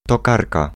Ääntäminen
France: IPA: [ɛ̃ tuʁ]